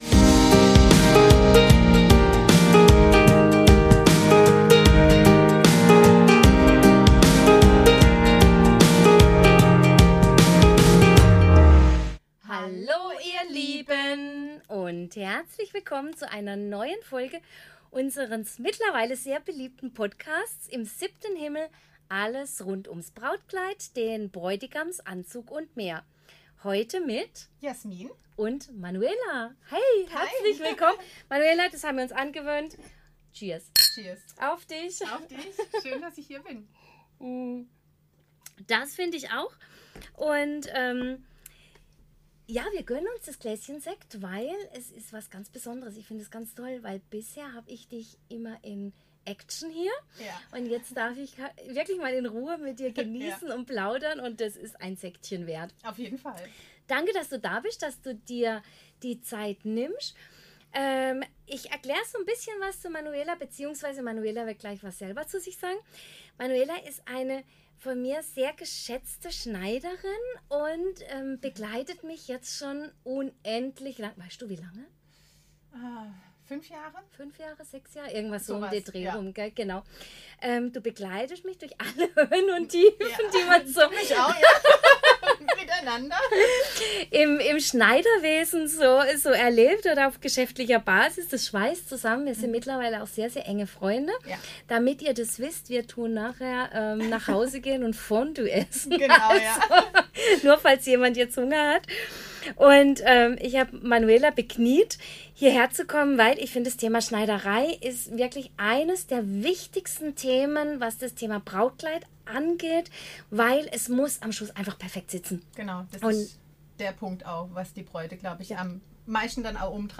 In dieser Episode spreche ich mit einer erfahrenen Schneiderin über alles, was du zur Änderung deines Brautkleides wissen musst.